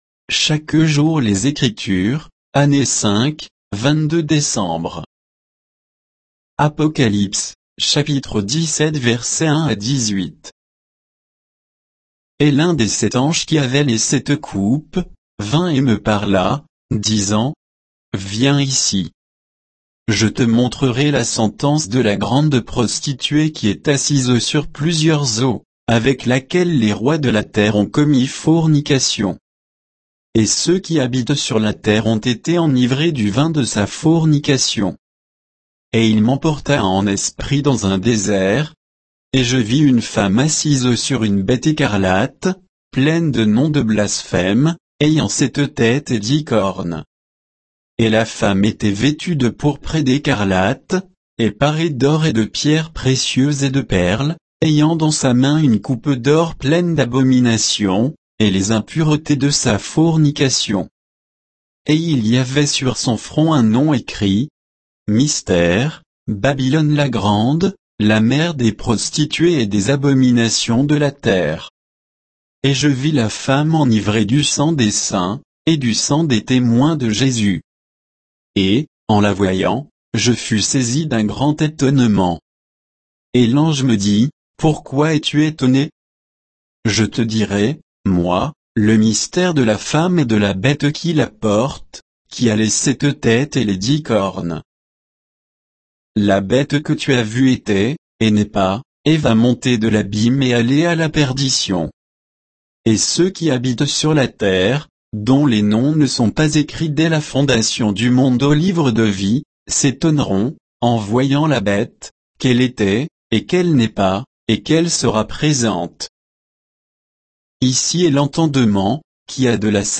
Méditation quoditienne de Chaque jour les Écritures sur Apocalypse 17, 1 à 18